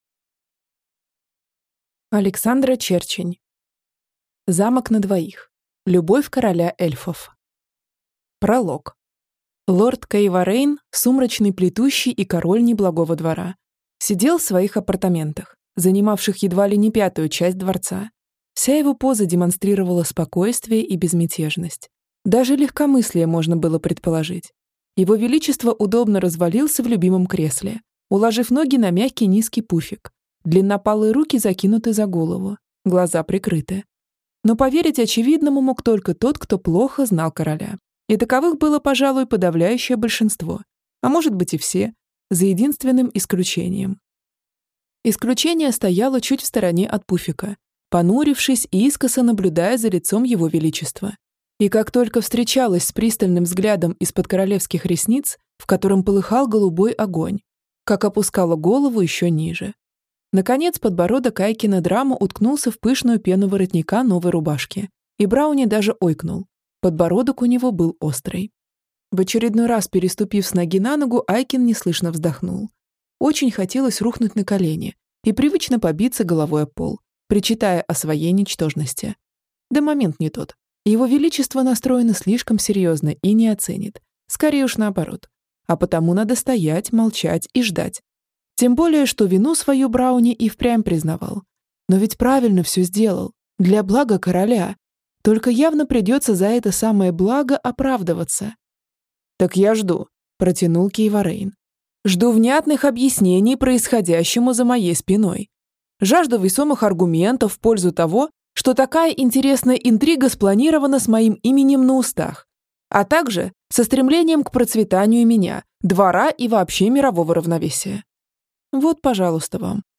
Аудиокнига Замок на двоих. Любовь короля эльфов | Библиотека аудиокниг
Прослушать и бесплатно скачать фрагмент аудиокниги